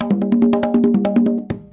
Sons et loops de percussions gratuits
Banque sons : Batterie
Percus loop 31